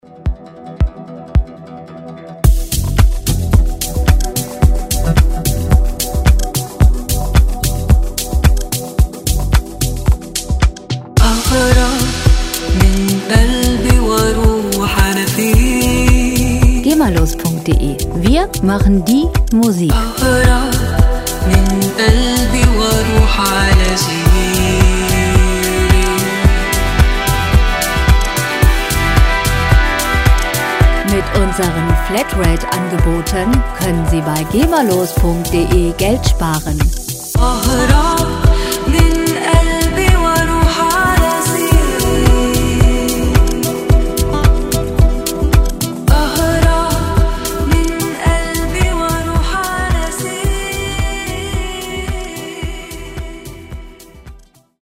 • Arabian Chill House